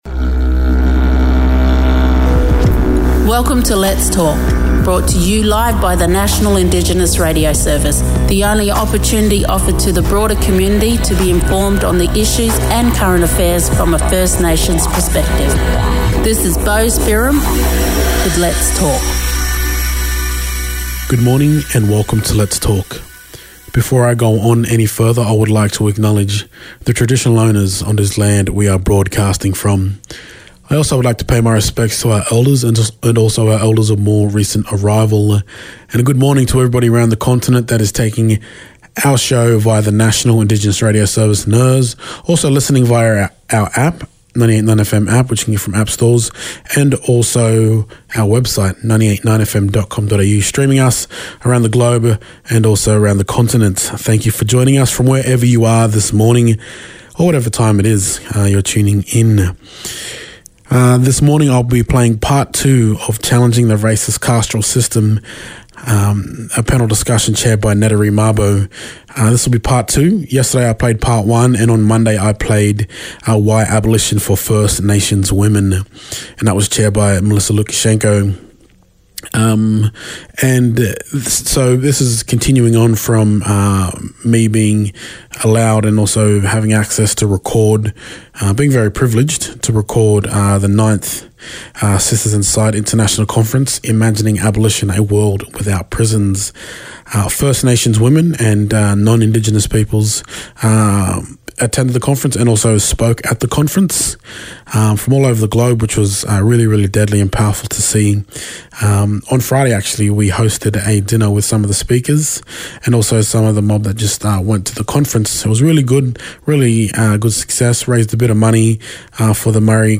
A panel discussion
recorded while attending the 9th Sisters Inside International Conference called Imagining Abolition A World Without Prisons